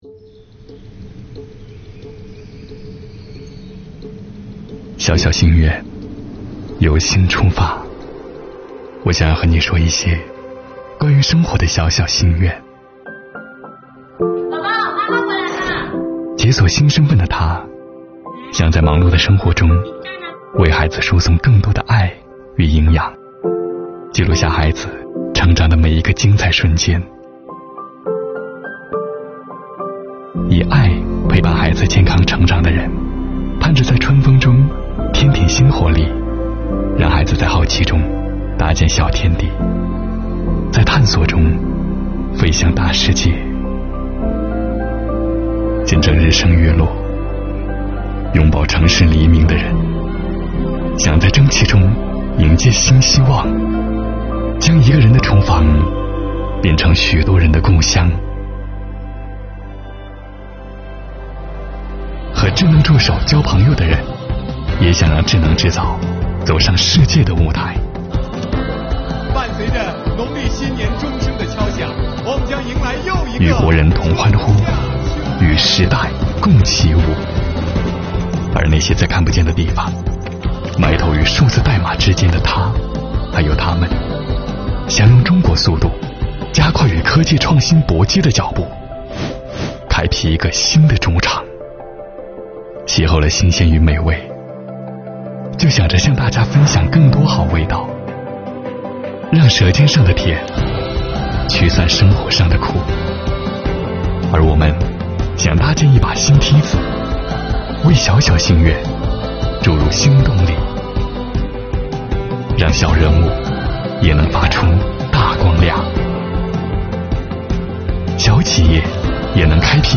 作品风格细腻，节奏舒缓，画面富有电影质感，以小见大，娓娓道来，从不同行业不同角度切入，展示了减税降费助力伟大理想和小小心愿一同实现的主题。